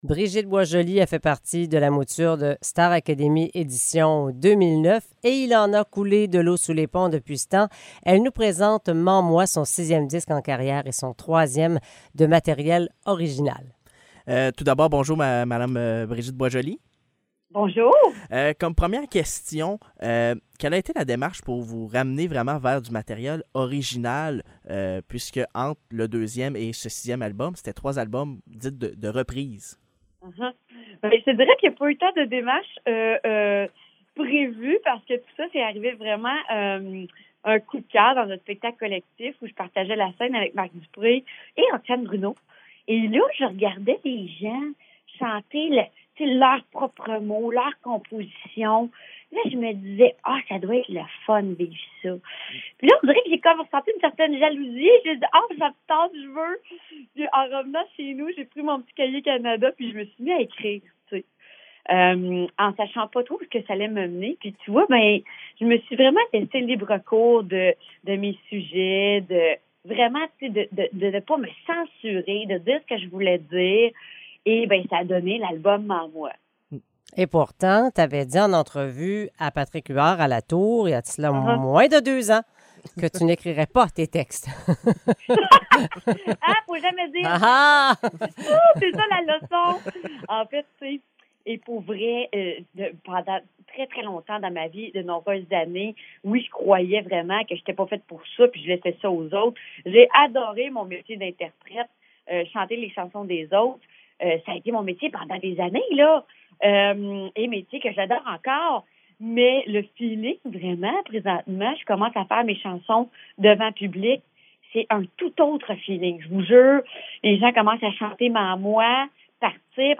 Entrevue avec Brigitte Boisjoli